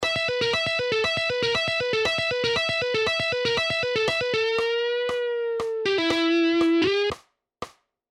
Pentatonic Scale Guitar Licks 5
Here is the lick 5 in Original Speed:
Pentatonic-Scale-Guitar-Licks-5.mp3